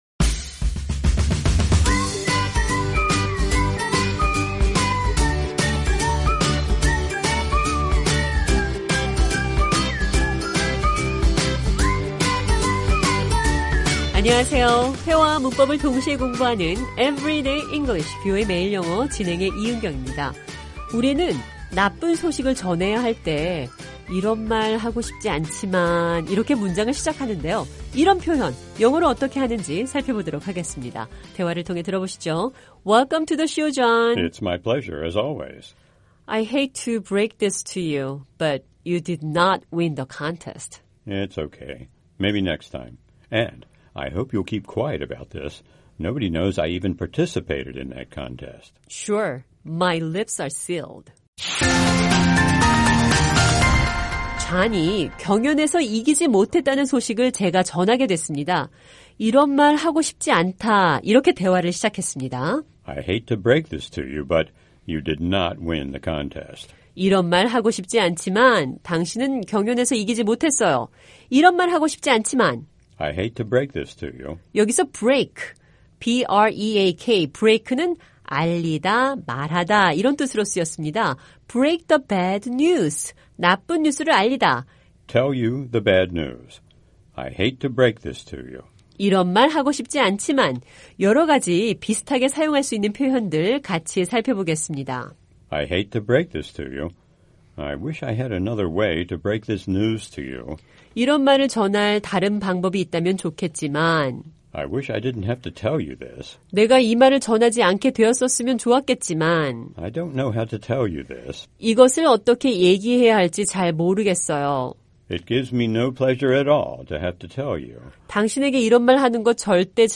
이런 표현 영어로 어떻게 하는지 살펴보겠습니다. 대화를 통해 들어보시죠.